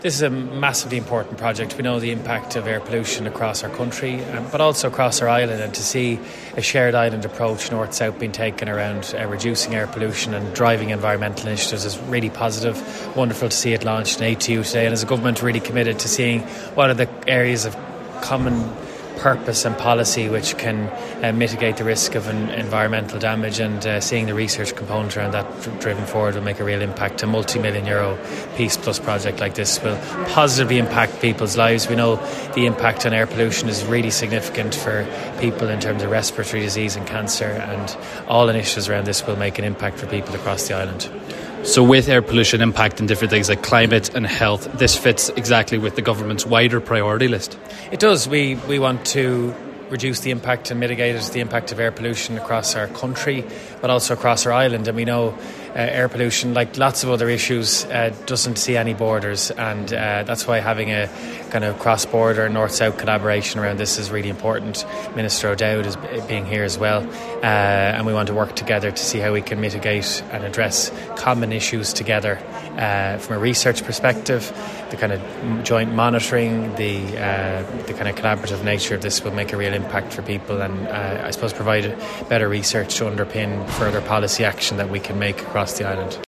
Jack Chambers was speaking at the ATU in Letterkenny  yesterday afternoon, where he attended the launch of PEACE-Air, an all-Ireland initiative focused on improving air quality in Ireland, led by Letterkenny based ERNACT.
Minister Chambers told Highland Radio News that cross border collaboration, particularly in the area of research, will benefit everyone on hew island of Ireland…….